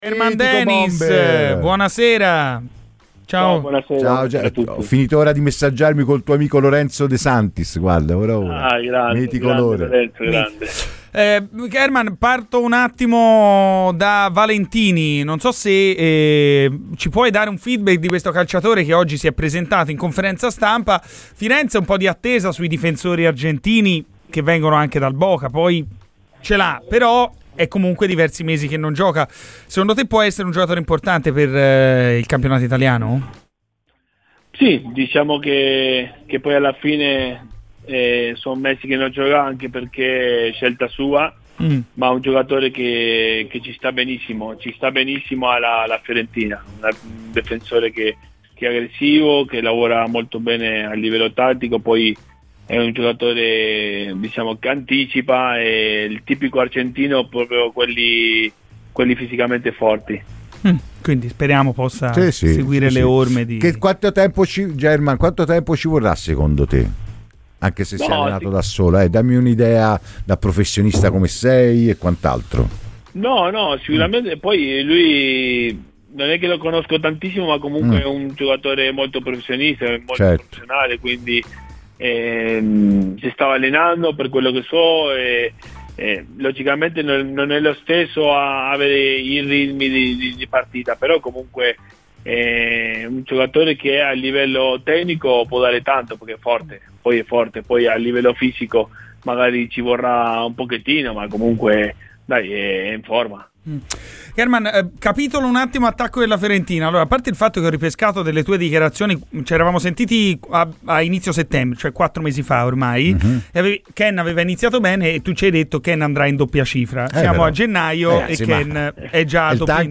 Ospite di Radio FirenzeViola nel corso della trasmissione "Garrisca al Vento", l'ex attaccante argentino - ora opinionista - German Denis ha parlato così di Fiorentina partendo da Valentini: "Può essere un giocatore che ci sta benissimo in viola e in Italia.